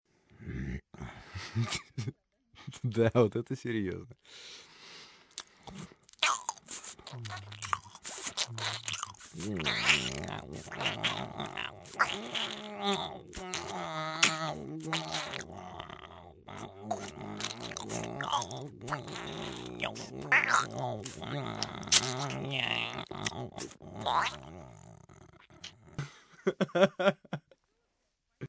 insect.mp3